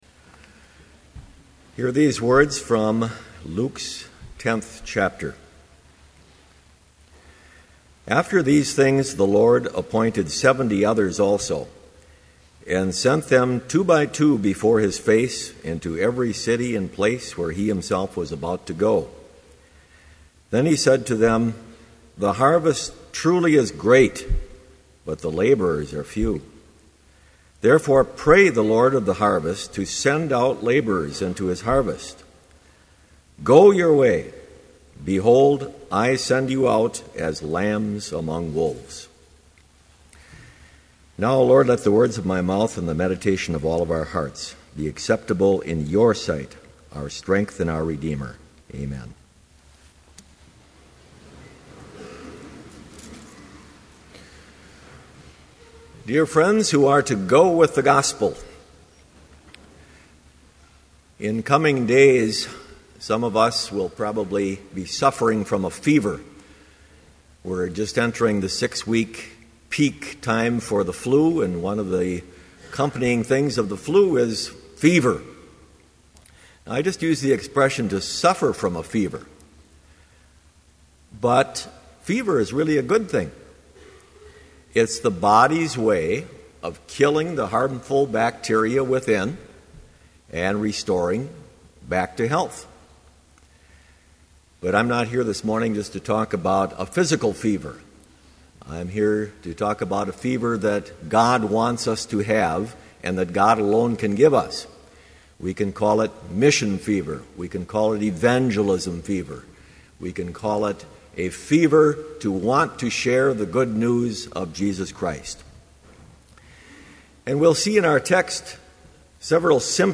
Complete service audio for Chapel - January 24, 2012